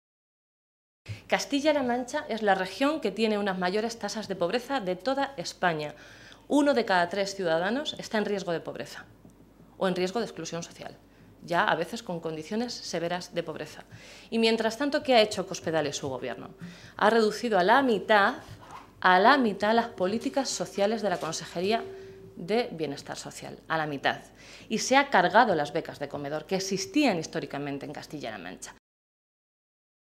Blanca Fernández, portavoz de Educación del Grupo Parlamentario Socialista
Cortes de audio de la rueda de prensa